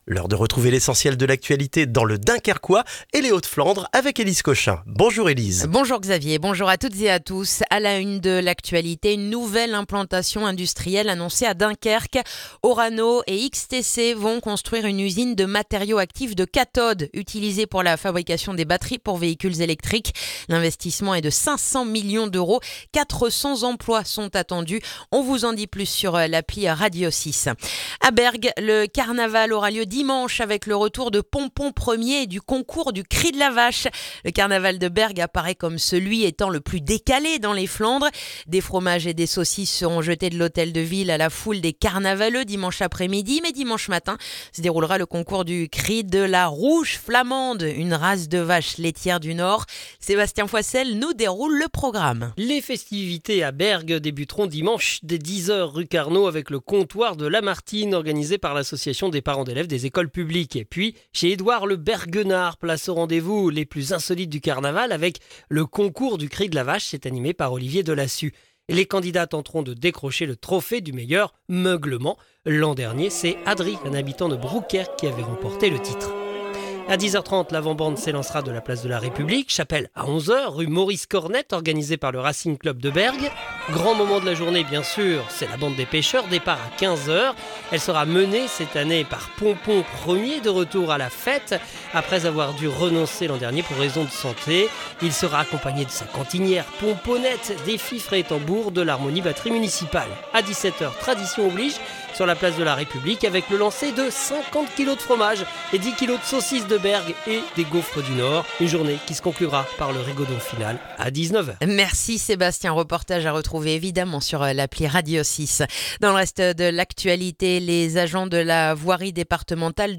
Le journal du vendredi 6 mars dans le dunkerquois